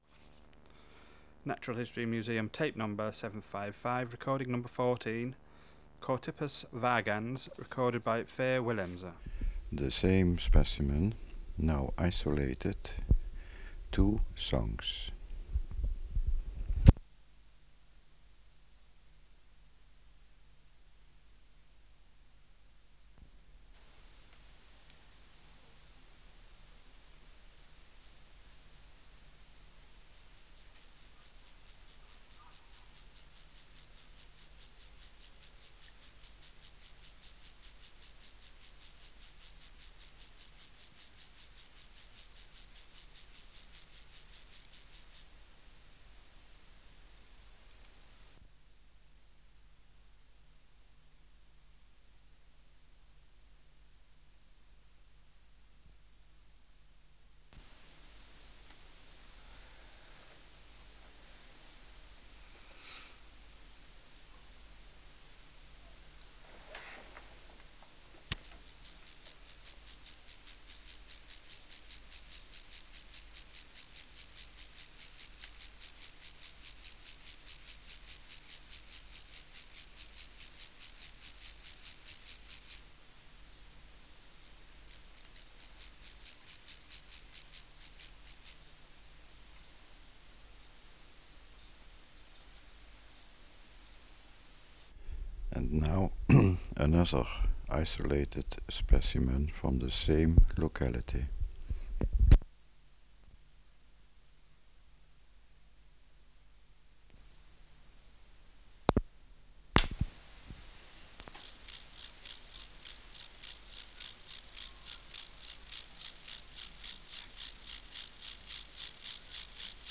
Species: Chorthippus (Glyptobothrus) vagans
Recording Location: Room in private house.
Air Movement: Nil Light: In shade Substrate/Cage: In cage
Isolated male Both hind legs intact
Microphone & Power Supply: AKG D202E (LF circuit off) Distance from Subject (cm): 8 Windshield: On base Recorder Recorder: Uher 4200